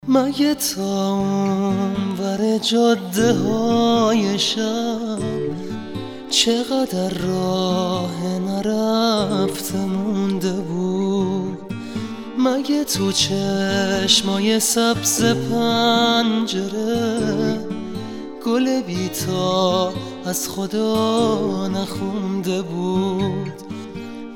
زنگ موبایل رمانتیک و با کلام